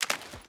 Water Jump.ogg